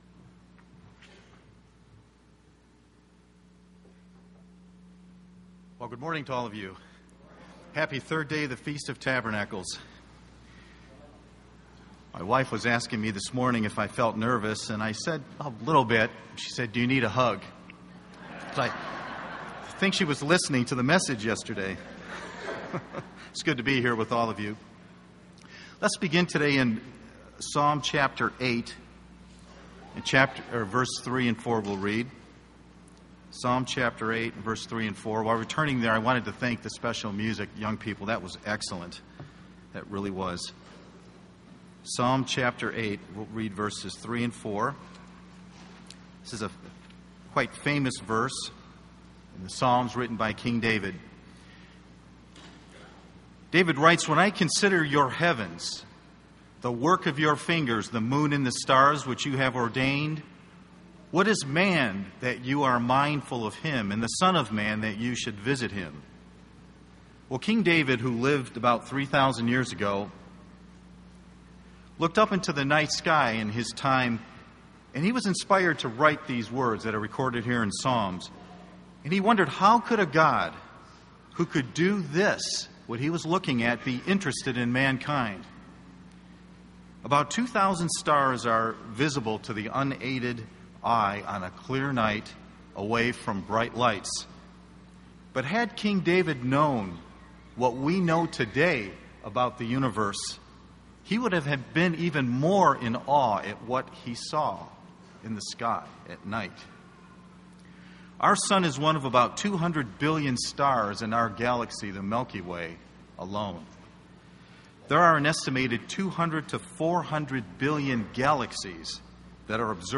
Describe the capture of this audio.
This sermon was given at the Sevierville, Tennessee 2015 Feast site.